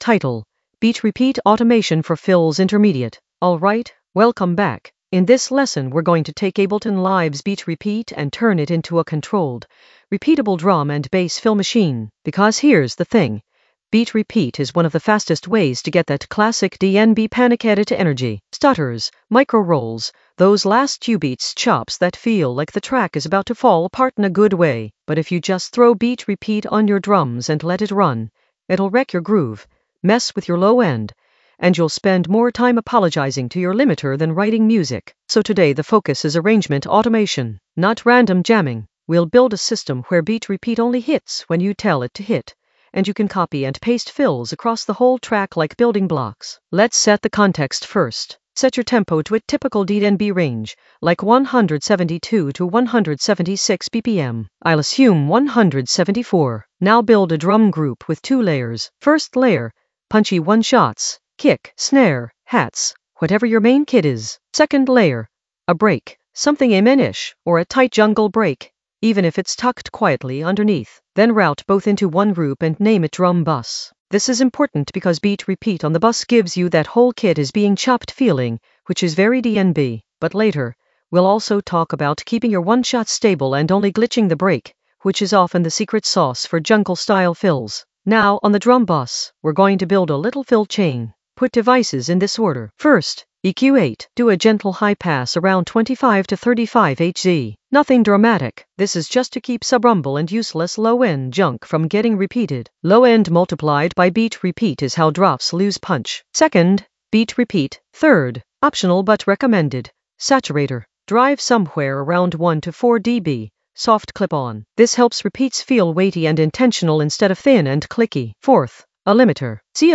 Narrated lesson audio
The voice track includes the tutorial plus extra teacher commentary.
beat-repeat-automation-for-fills-intermediate-automation.mp3